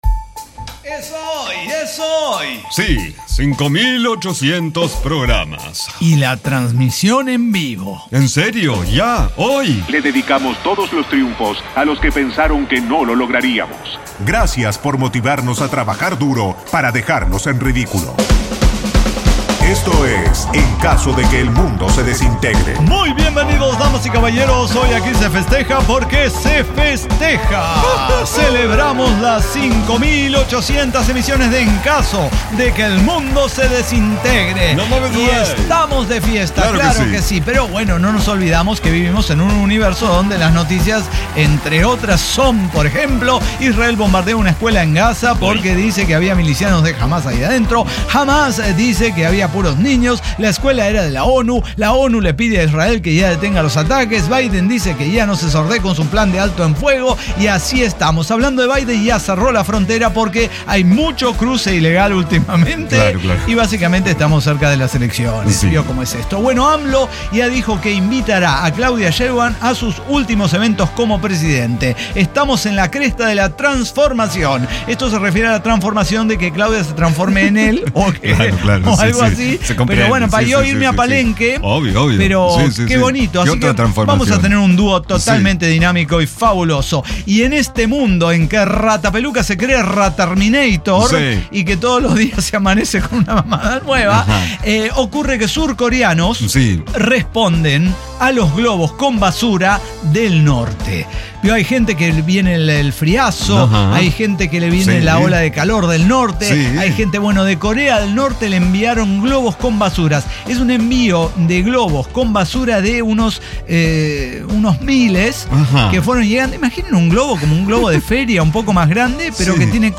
El Cyber Talk Show